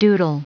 Prononciation du mot doodle en anglais (fichier audio)
Prononciation du mot : doodle
doodle.wav